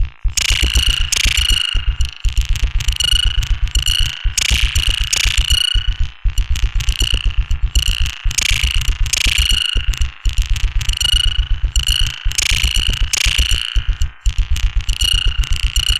Abstract Rhythm 28.wav